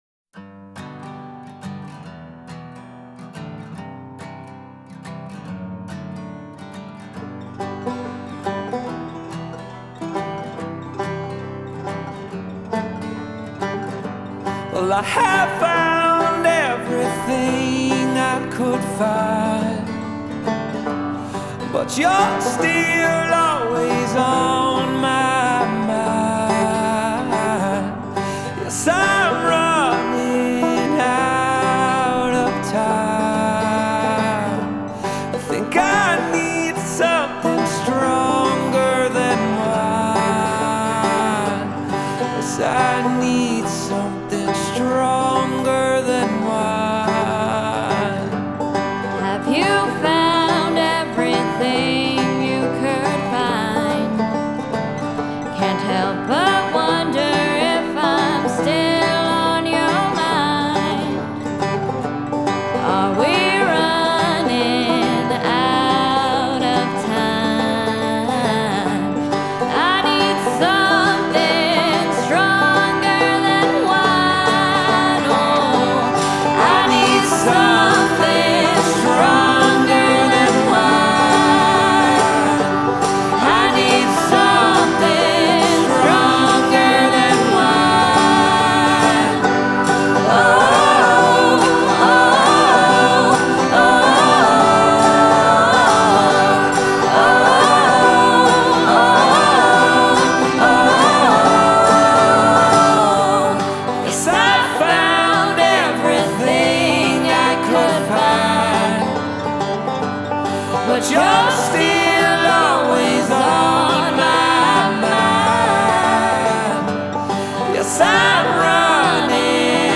country rock
is a banjo-driven, melodic traditional-ish number